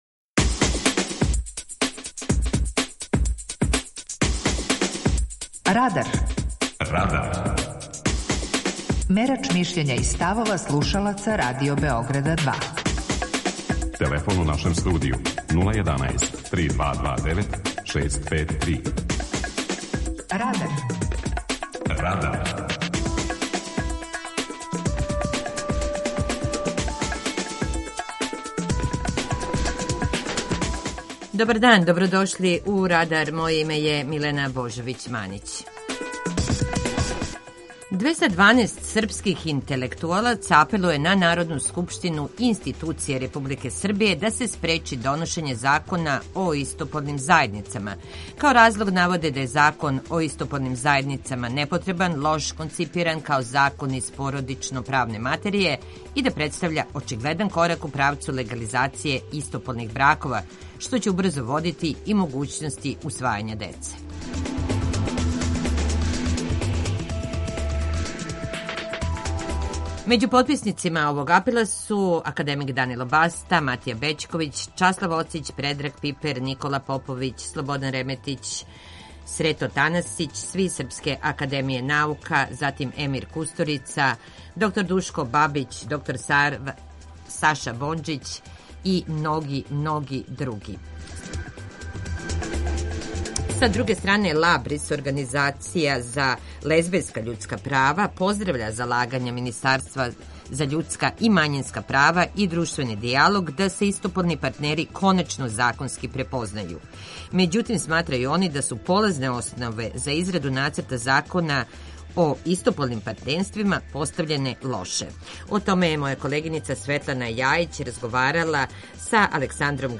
Питање Радара: Да ли подржавате Закон о истополном партнерству? преузми : 19.24 MB Радар Autor: Група аутора У емисији „Радар", гости и слушаоци разговарају о актуелним темама из друштвеног и културног живота.